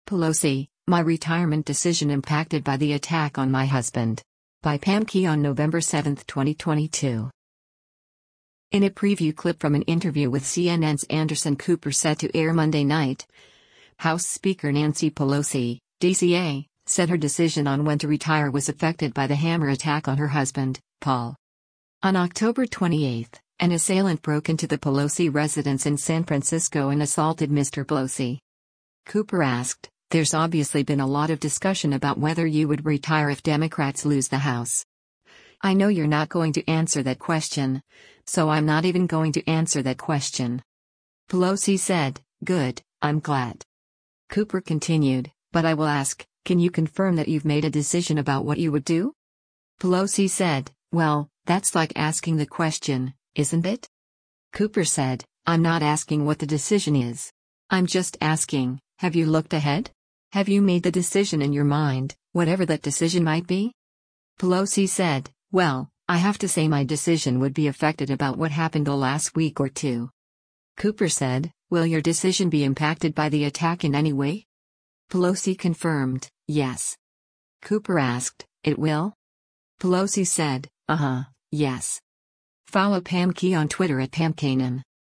In a preview clip from an interview with CNN’s Anderson Cooper set to air Monday night, House Speaker Nancy Pelosi (D-CA) said her decision on when to retire was affected by the hammer attack on her husband, Paul.